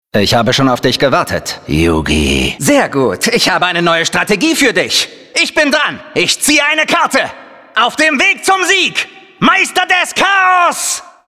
sehr variabel
Mittel minus (25-45)